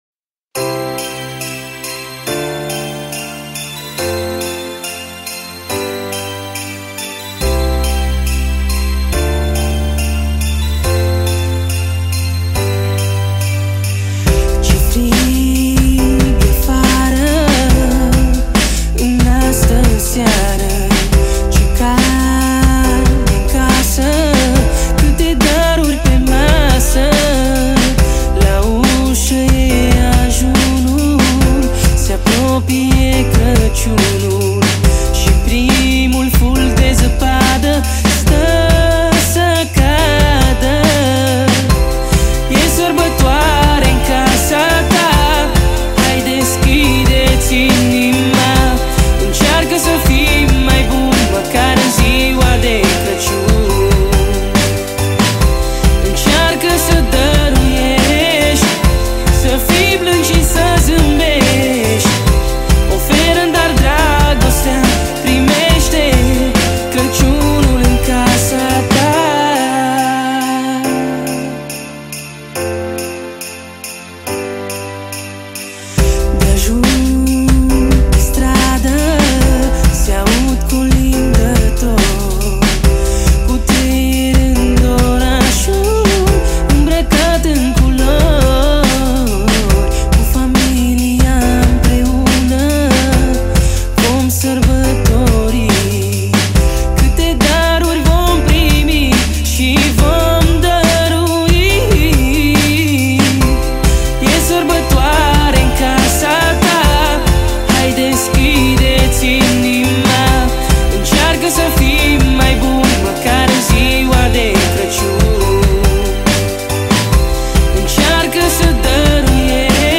Data: 29.09.2024  Colinde Craciun Hits: 0